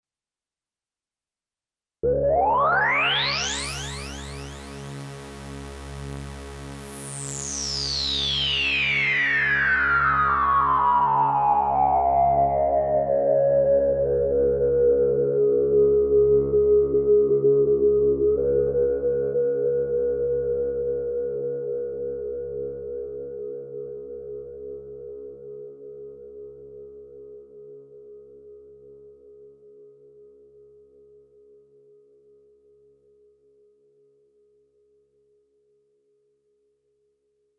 Il secondo ascolto (XioSynth02.mp3) permette di apprezzare come suona il filtro pressoché in tutta la sua estensione.